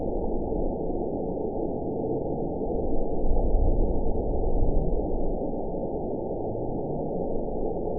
event 919820 date 01/25/24 time 09:50:51 GMT (1 year, 10 months ago) score 9.06 location TSS-AB01 detected by nrw target species NRW annotations +NRW Spectrogram: Frequency (kHz) vs. Time (s) audio not available .wav